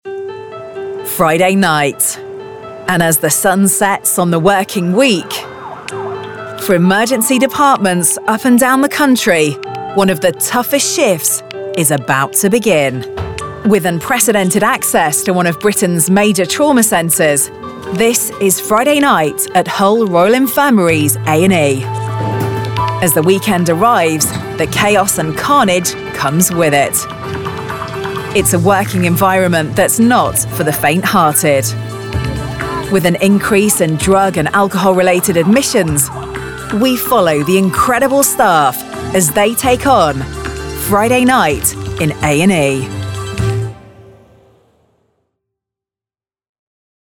Native Accent: Neutral Characteristics: Warm and Engaging Age: 30-40 Age: 40-50 View on spotlight Commercial Commercial - upbeat Corporate Charity Documentary